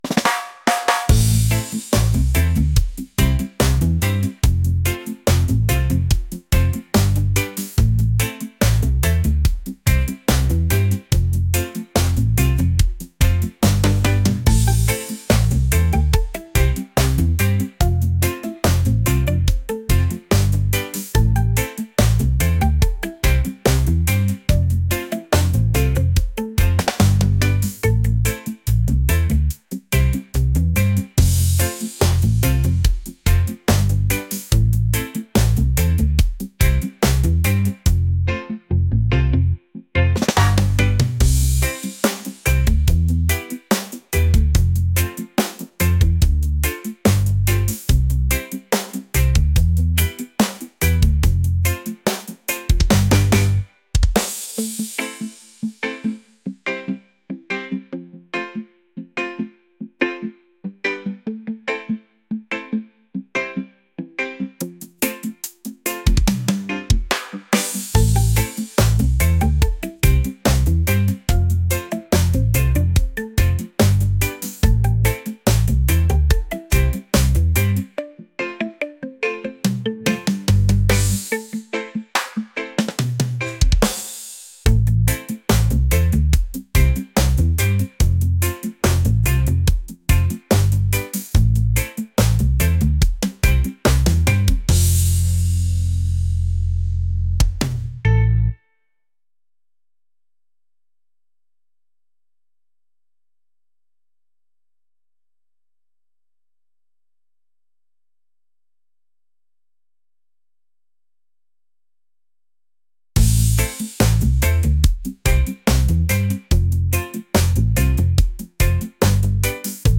laid-back | reggae | catchy